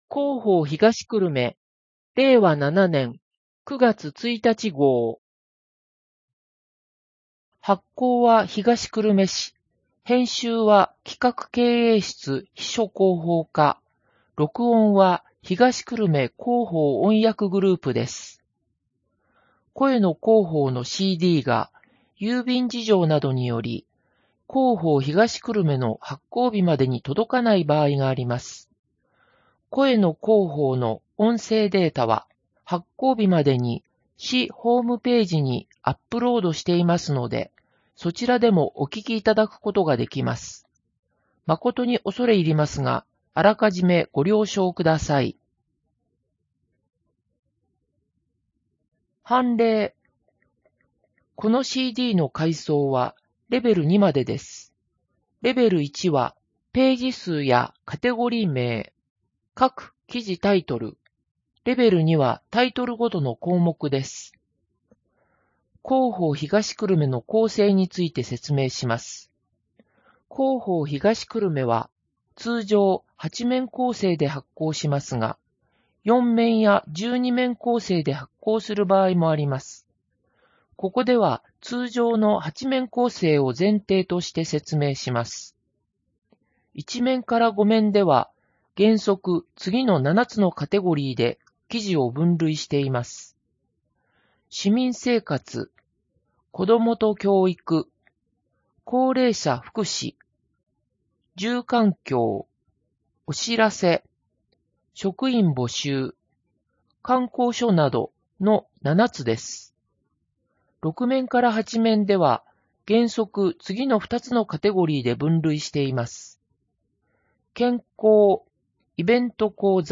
声の広報（令和7年9月1日号）